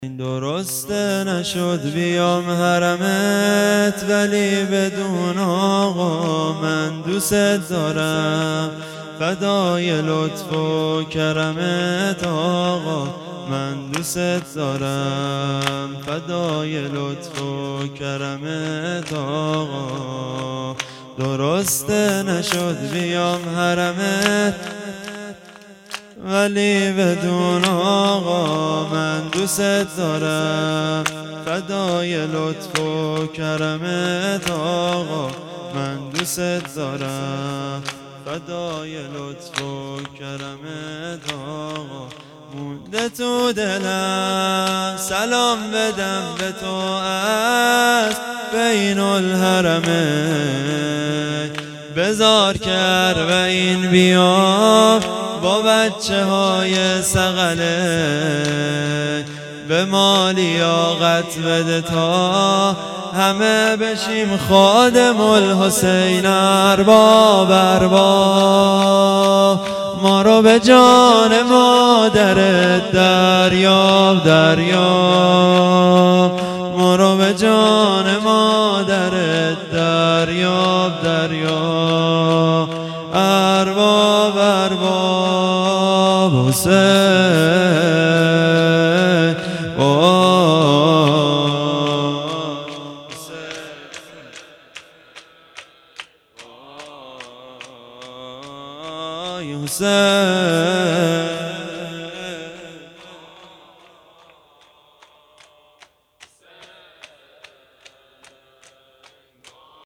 شب پنجم محرم ۱۴۴۴